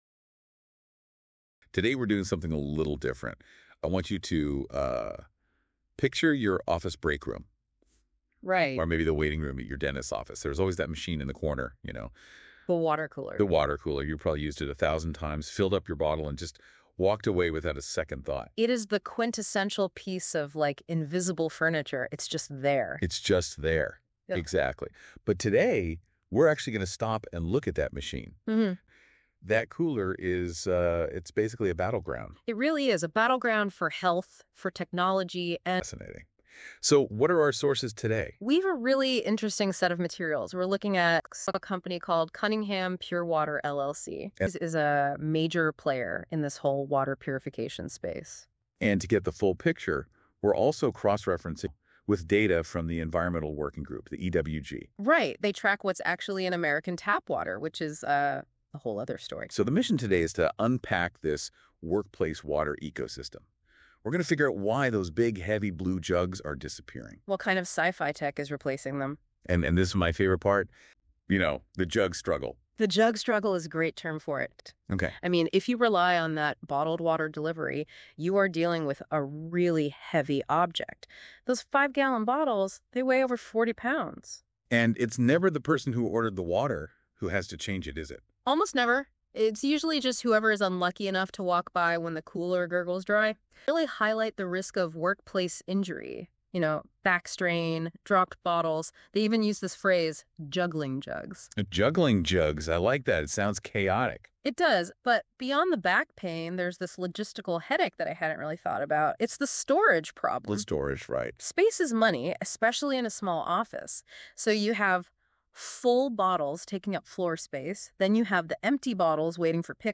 High Quality Audio